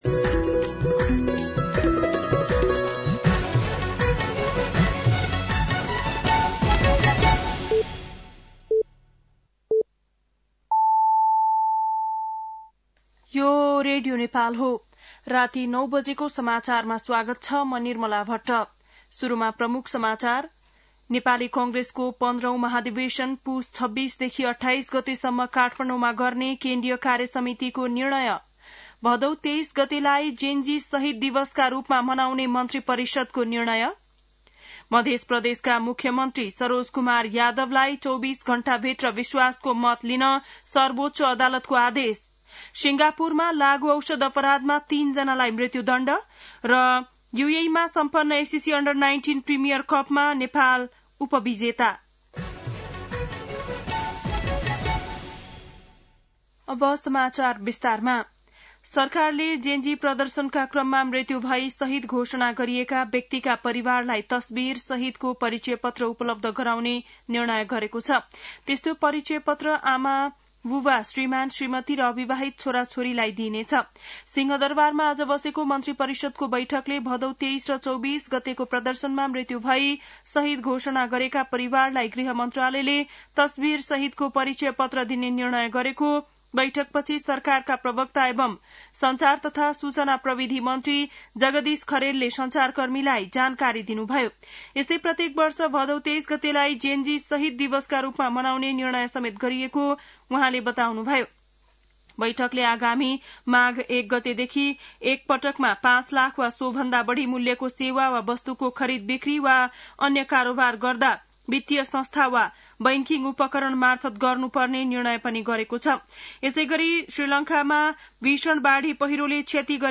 बेलुकी ९ बजेको नेपाली समाचार : १५ मंसिर , २०८२
9-PM-Nepali-NEWS-8-15.mp3